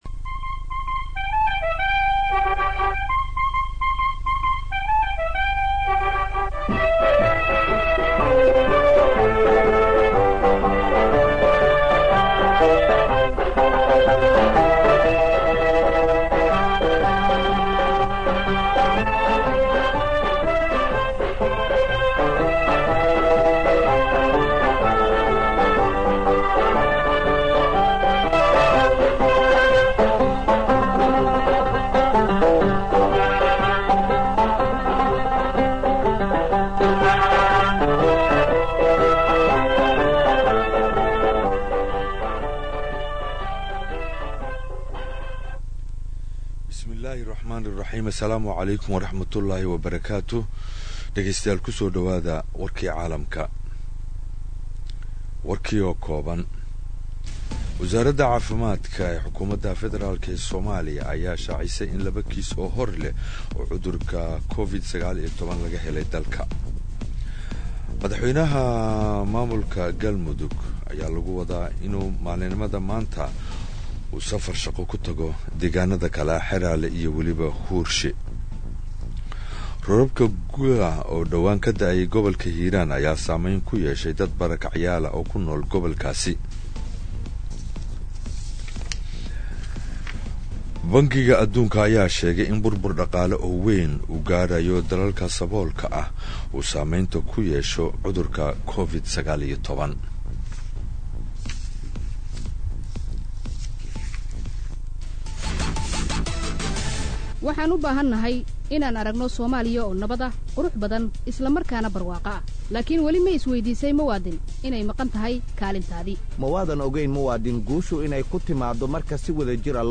Dhageyso warka subax ee radiomuqdisho Sabti 04.03.2020